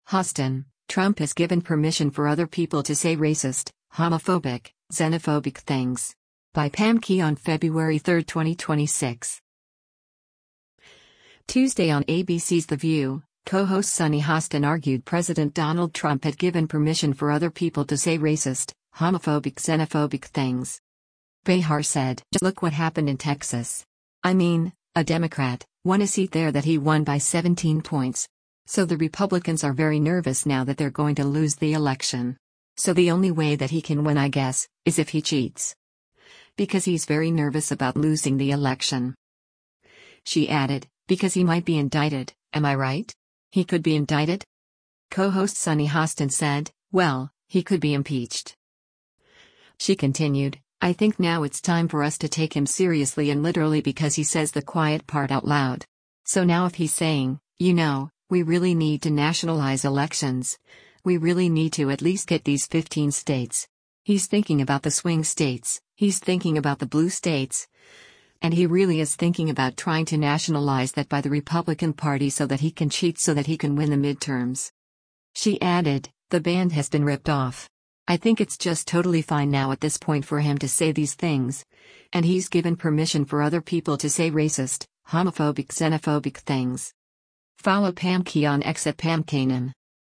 Tuesday on ABC’s “The View,” co-host Sunny Hostin argued President Donald Trump had “given permission for other people to say racist, homophobic xenophobic things.”